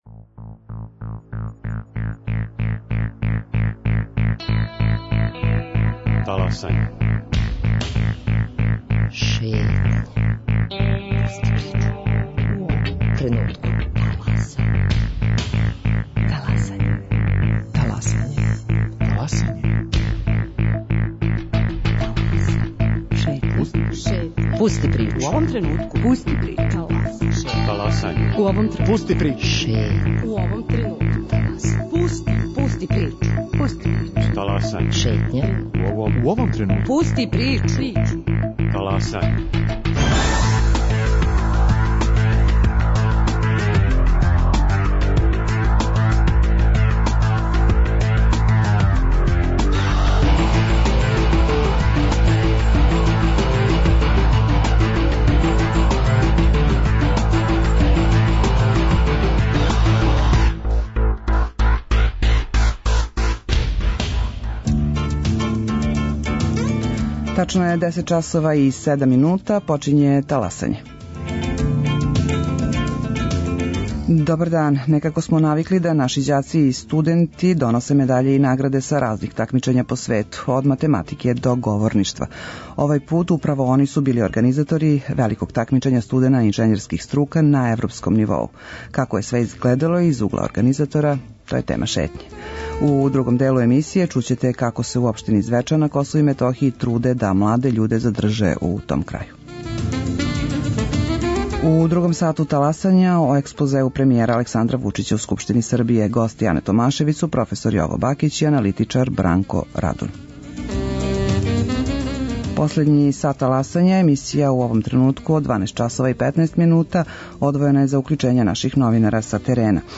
У другом делу емисије чућете разговор